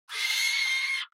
دانلود صدای شب 10 از ساعد نیوز با لینک مستقیم و کیفیت بالا
جلوه های صوتی